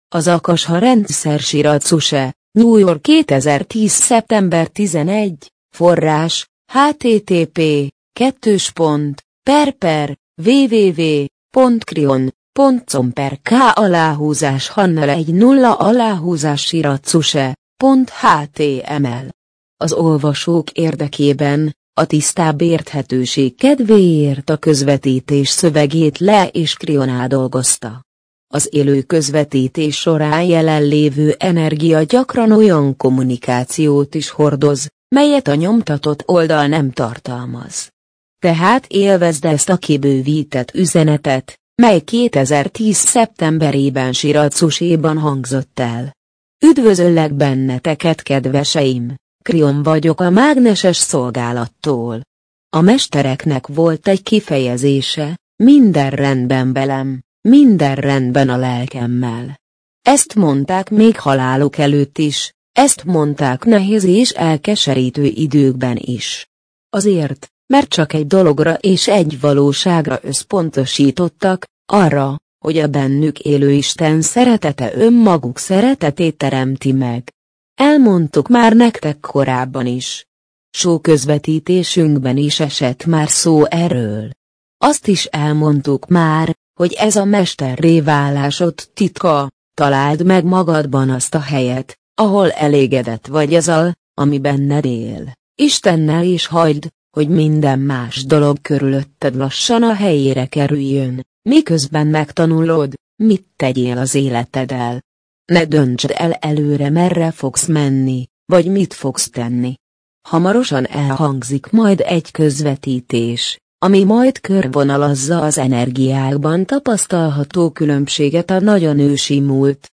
MP3 gépi felolvasás Az Akasha rendszer Az Akasha rendszer Syracuse, New York - 2010. szeptember 11.